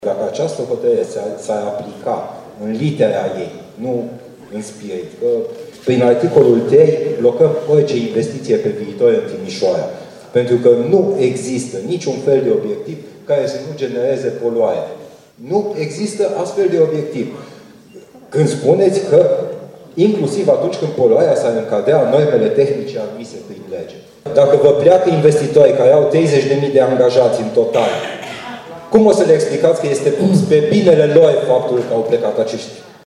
Prefectura a înaintat un recurs grațios și în privința deciziei care viza măsuri de reducere a poluării. Consilierul independet Flavius Boncea a solicitat ca și această hotărâre să fie revocată:
flavius-boncea.mp3